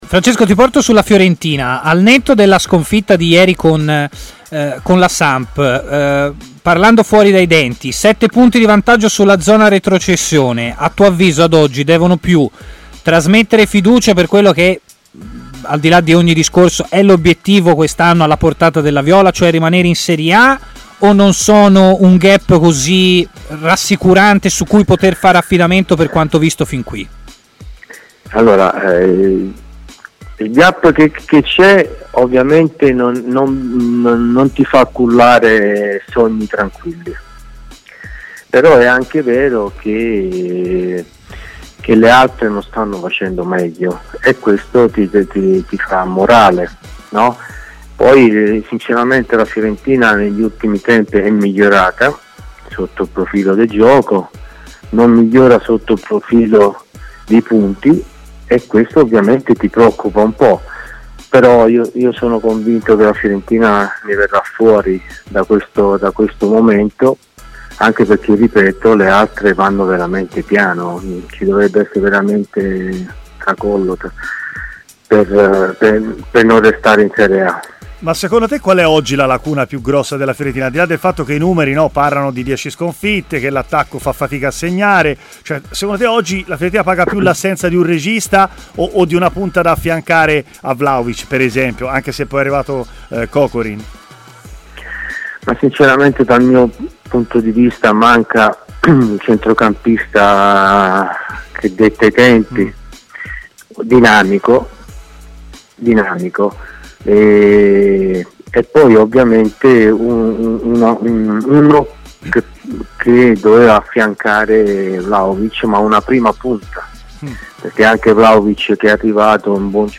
L'ex attaccante viola Ciccio Baiano ha parlato a Stadio Aperto su TMW Radio della situazione complicata in cui si trova la Fiorentina, con un piccolo excursus su Lukaku e un paragone importante, con bomber Batirstuta: "Fisicamente Lukaku è devastante, forse Batistuta segnava di più e giocava meno per la squadra: non farei però paragoni".